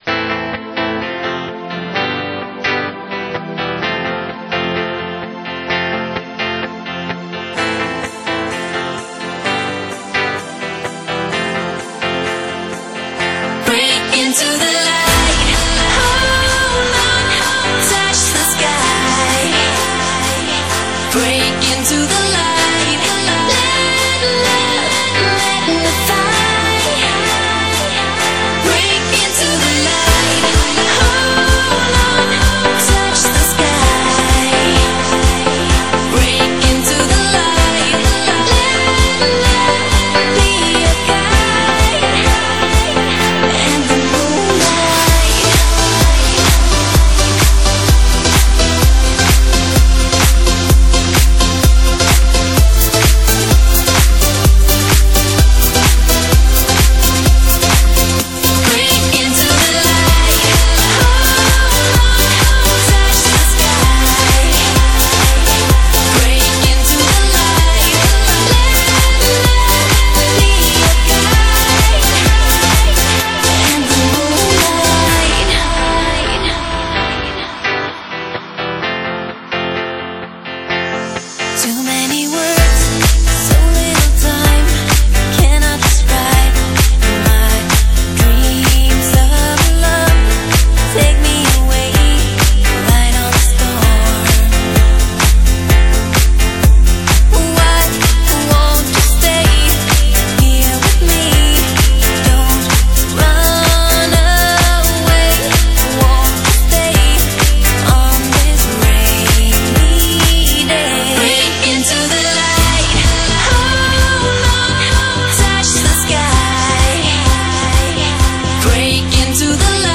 Genre: Dance, Pop |24 Tracks |  (百度盤)=242M